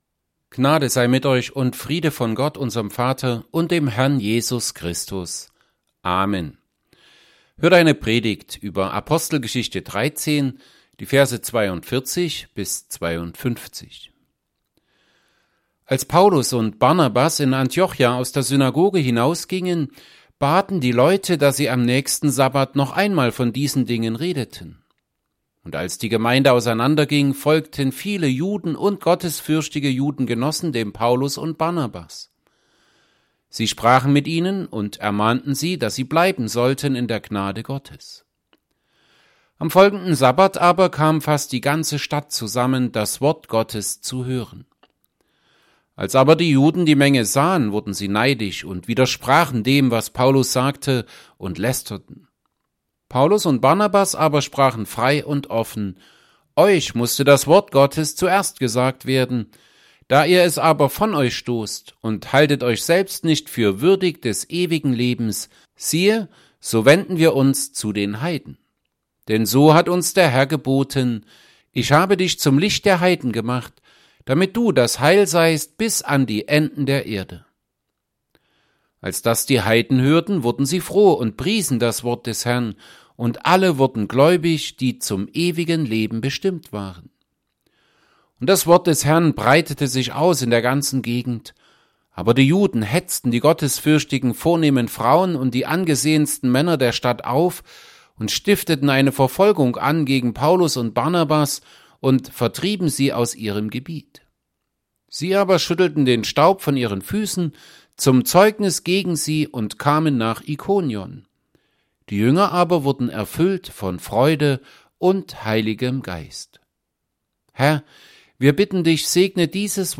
Serie: Epistelpredigten Passage: Acts 13:42-52 Gottesdienst